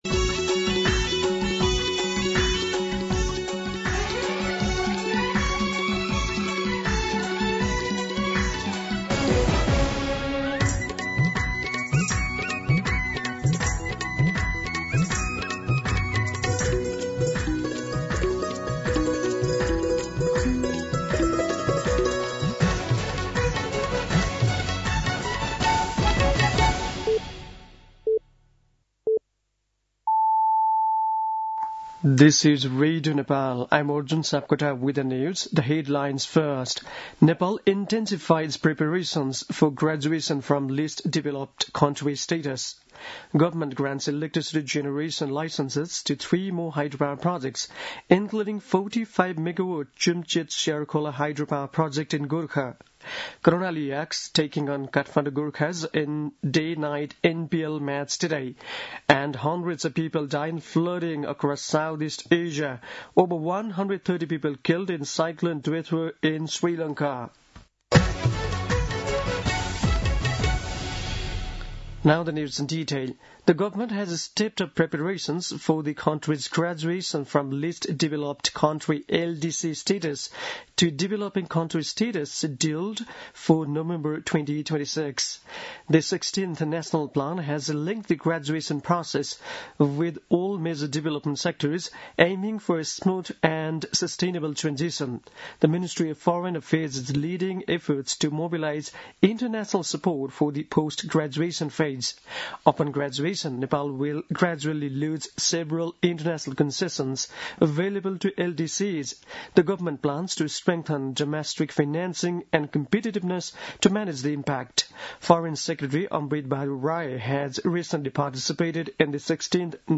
दिउँसो २ बजेको अङ्ग्रेजी समाचार : १४ मंसिर , २०८२